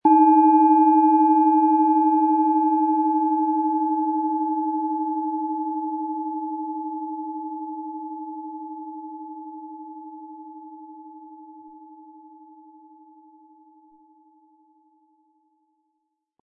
Planetenton 1
Um den Originalton der Schale anzuhören, gehen Sie bitte zu unserer Klangaufnahme unter dem Produktbild.
SchalenformBihar
MaterialBronze